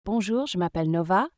🌐 Multilingual Voices
New multiligual voices available! Those voices can speak in any language depending on your scripts.